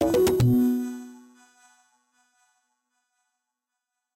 sfx_transition-08.ogg